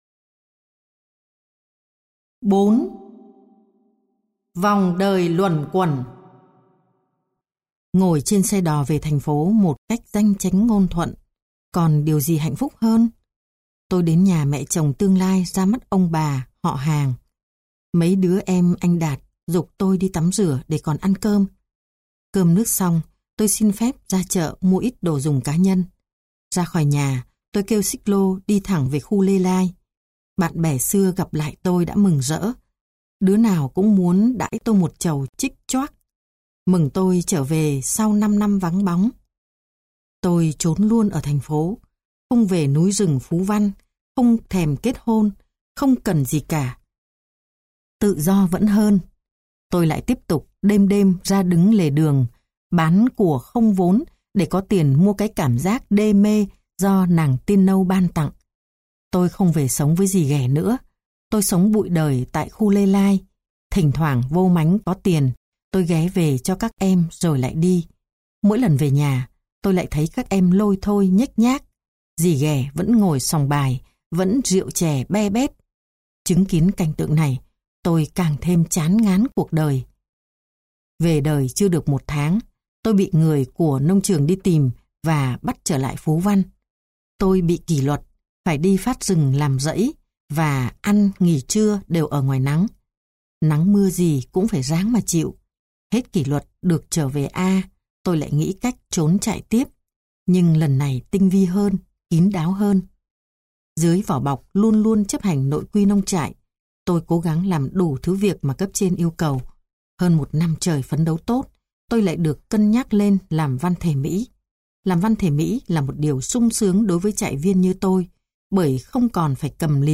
Sách nói Hồi ký tâm "Si- đa" vượt lên cái chết
THƯ VIỆN SÁCH NÓI HƯỚNG DƯƠNG DÀNH CHO NGƯỜI MÙ THỰC HIỆN